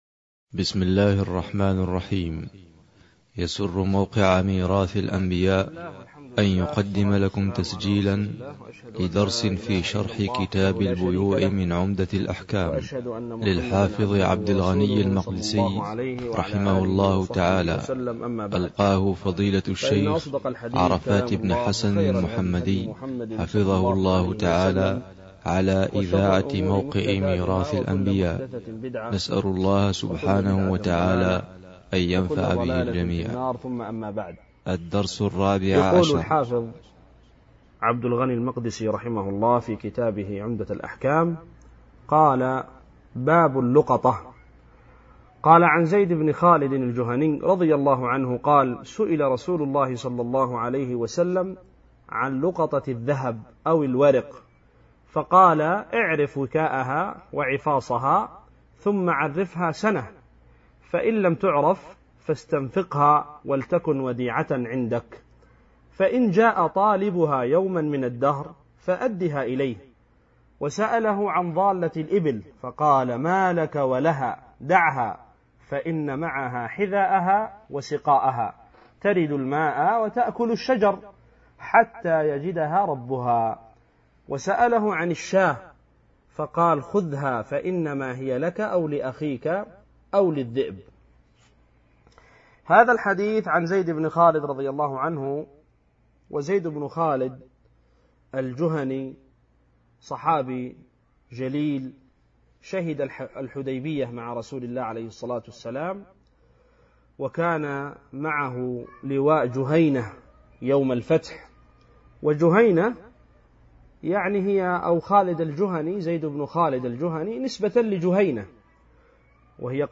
شرح